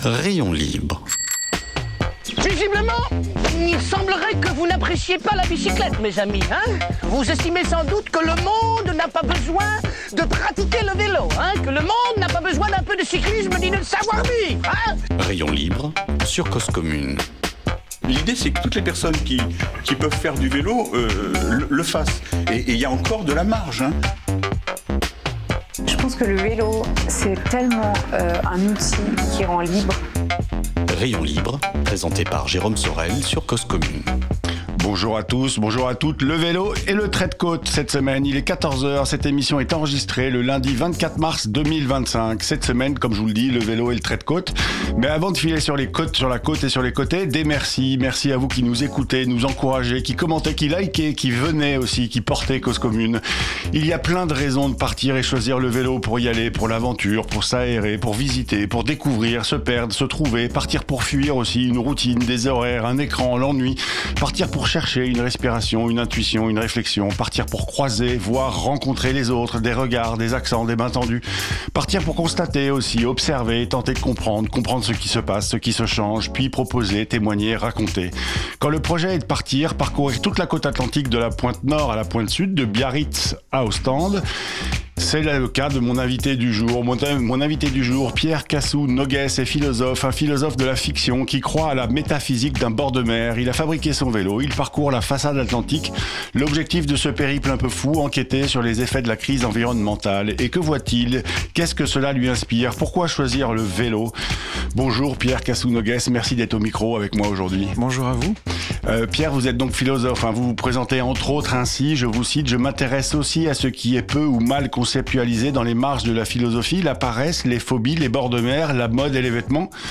Pas de pause musicale dans cet épisode qui fut enregistré courant mars.